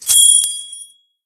bp_snout_coin_04.ogg